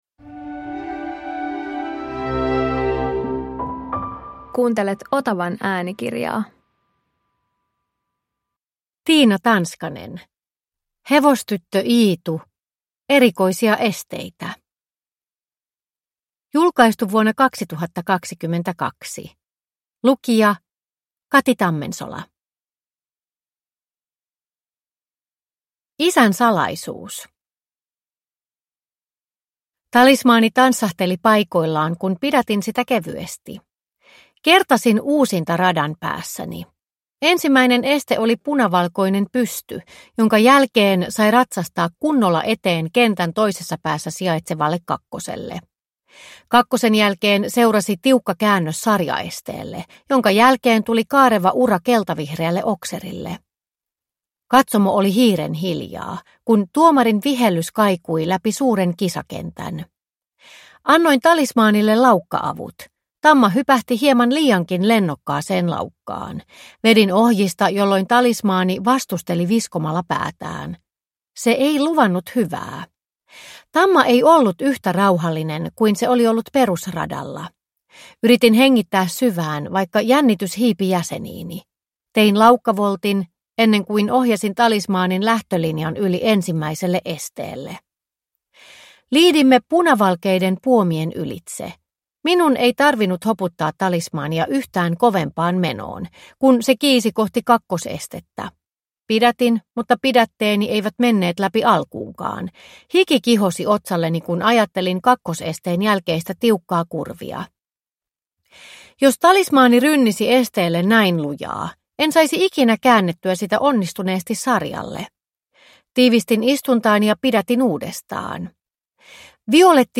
Erikoisia esteitä – Ljudbok – Laddas ner